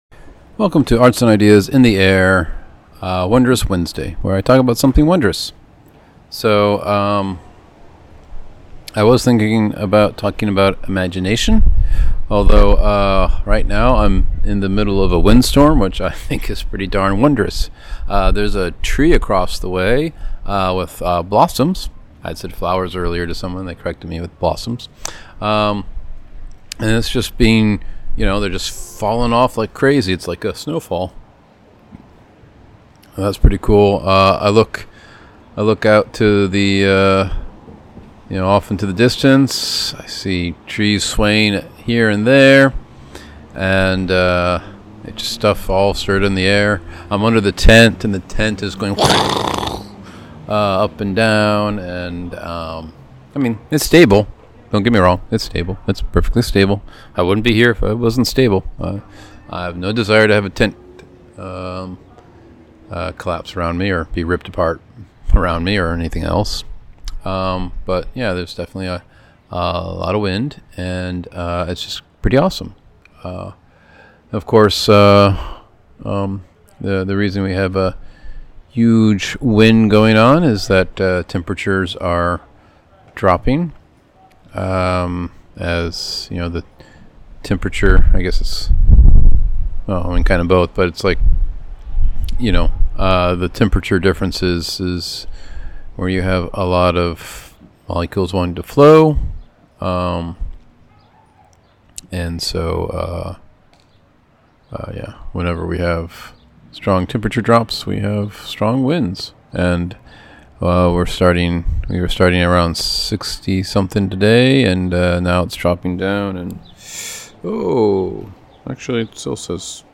Experiencing windstorm under tent.